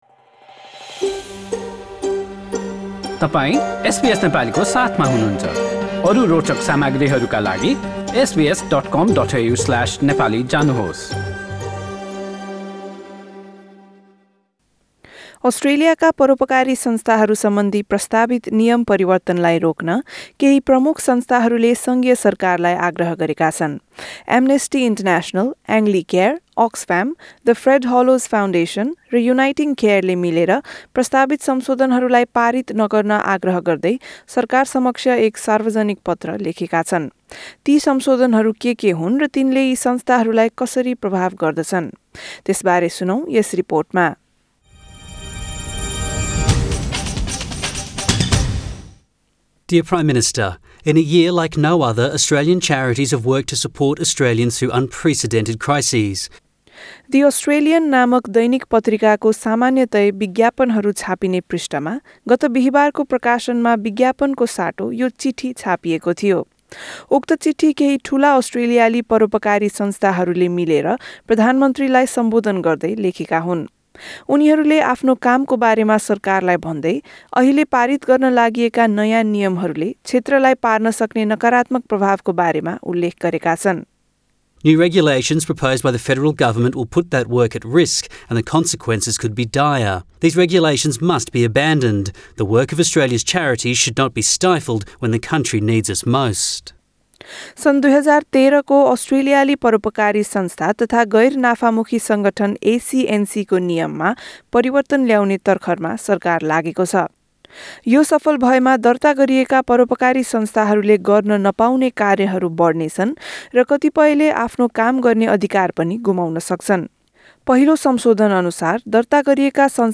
यसबारे रिपोर्ट सुन्नुहोस्: null हाम्रा थप अडियो प्रस्तुतिहरू पोडकास्टका रूपमा नि:शुल्क यहाँबाट डाउनलोड गर्न सक्नुहुन्छ।